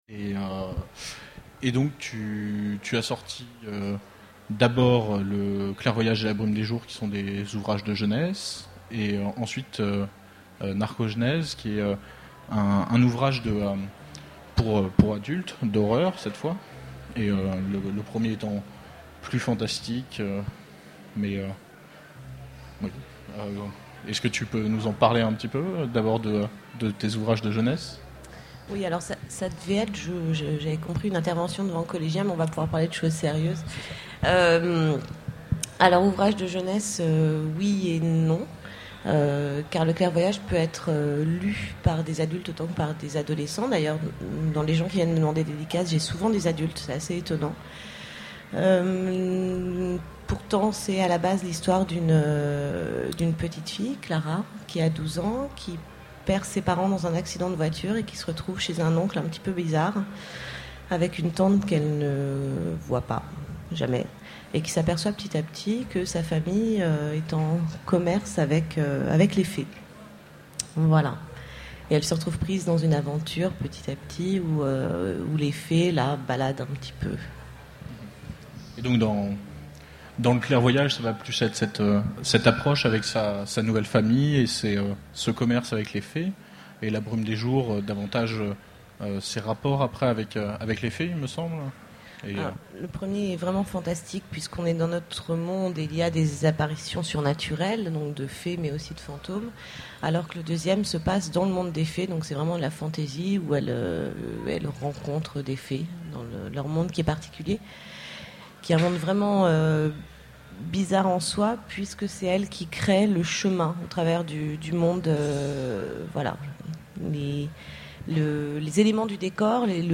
Conférence
Rencontre avec un auteur